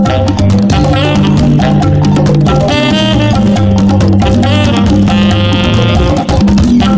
96RHYTH.mp3